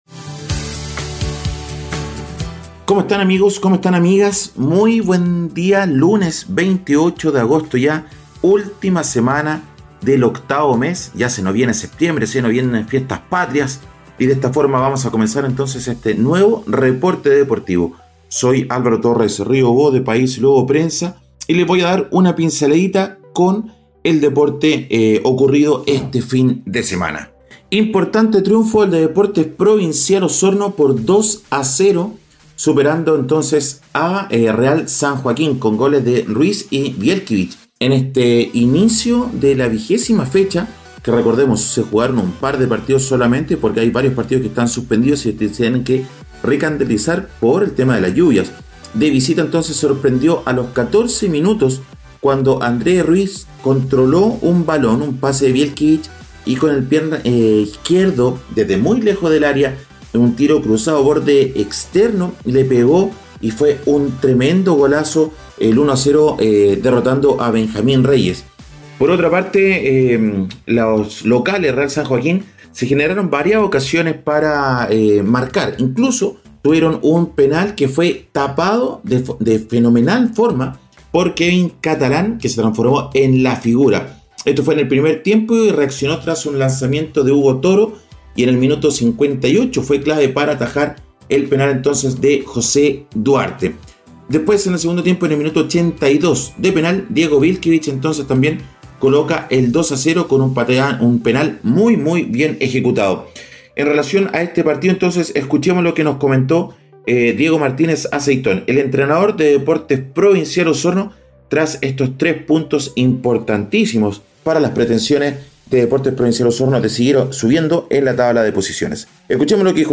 Reporte Deportivo 🎙 Podcast 28 de agosto de 2023